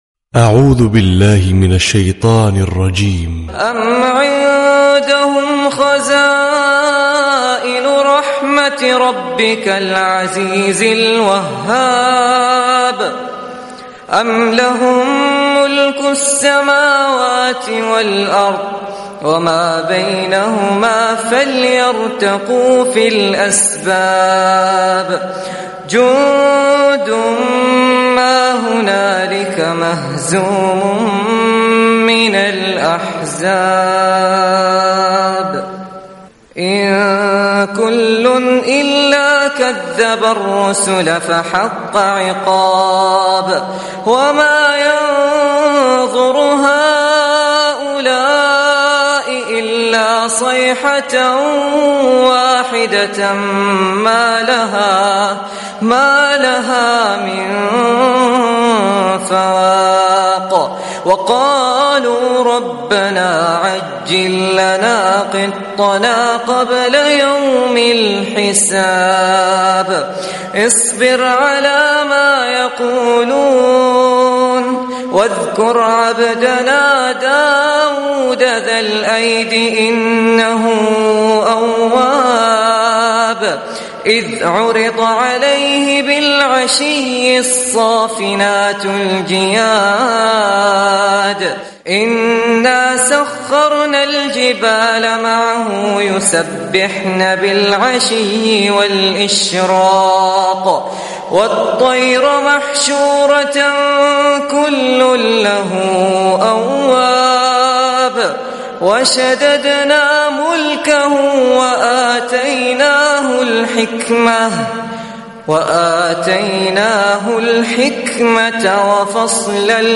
تلاوة صباحية